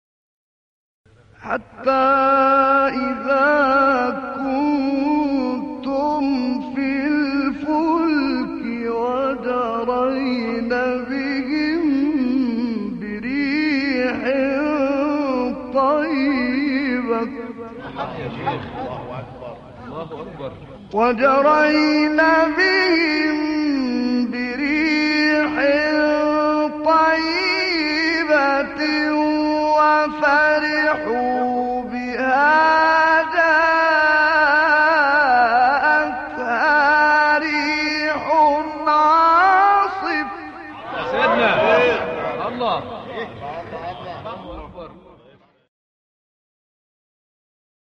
استاد «محمد عبدالعزیز حصان» یکی از مشاهیر تلاوت قرآن کریم است و به لحاظ برجستگی در ادای نغمات و دقت در ظرافت‌های وقف و ابتداء او را «ملک الوقف و الإبتدا و التنغیم» یعنی استاد الوقف و الابتداء و تلوین النغمی لقب داده‌اند.
در ادامه ۵ قطعه کوتاه از زیباترین تلاوت‌های استاد محمد عبدالعزیز حصان ارائه می‌شود.